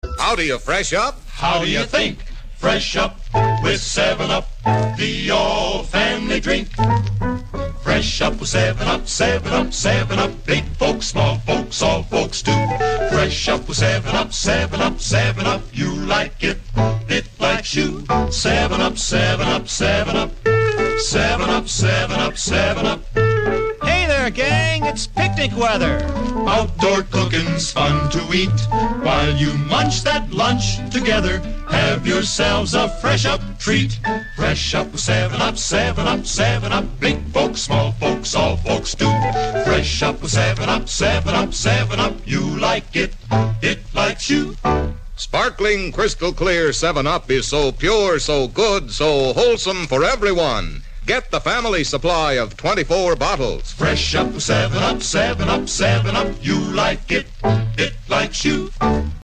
Classic Commercials